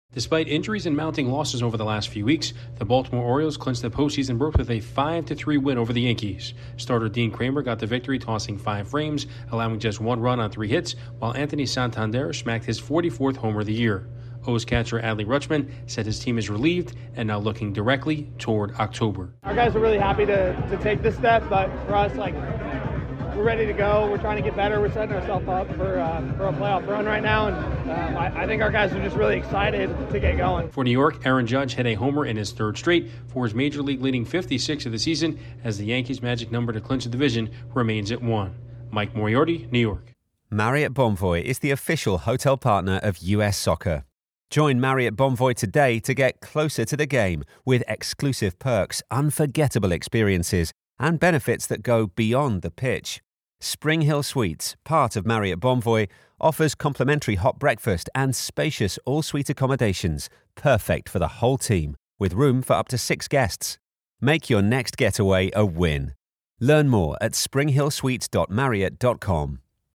The Orioles have reason to celebrate after defeating the Yankees. Correspondent